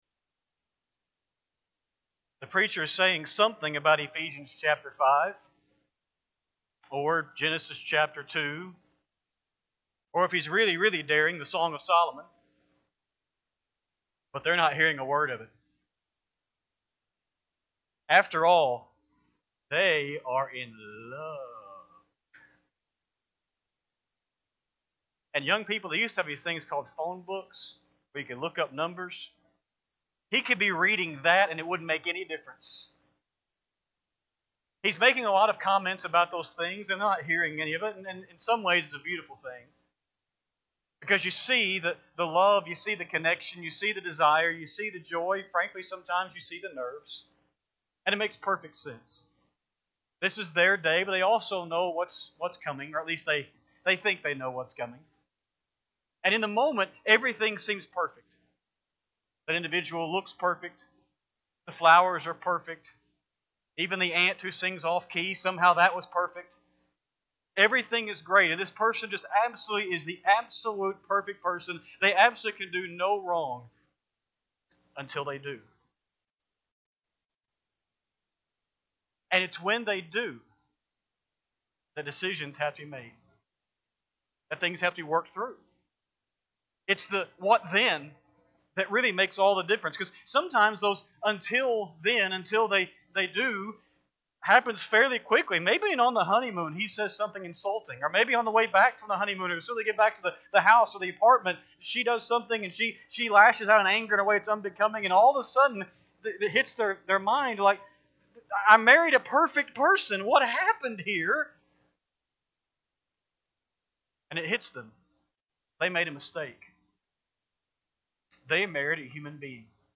3-15-26-Sunday-PM-Sermon.mp3